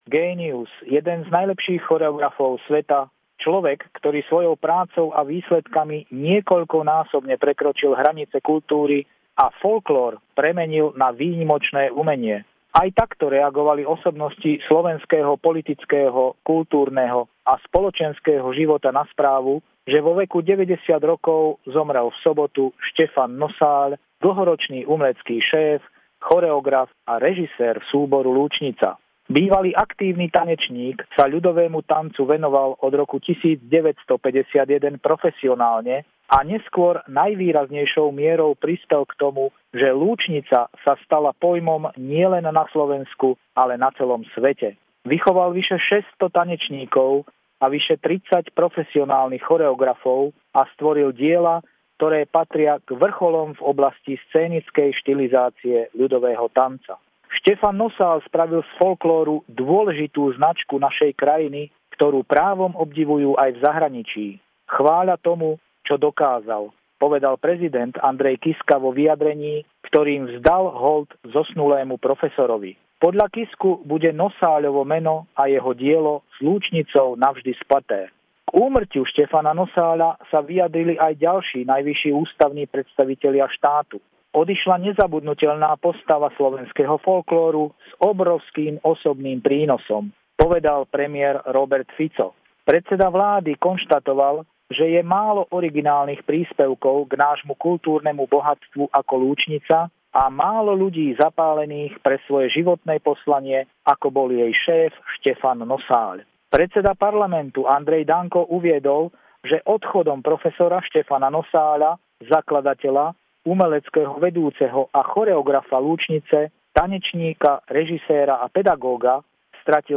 Legendárny folklórny umelec, choreograf, zakladateľ a azda aj otec Lúčnice Štefan Nosáľ zomrel 90-ročmý. Pravidelný telefonát týždňa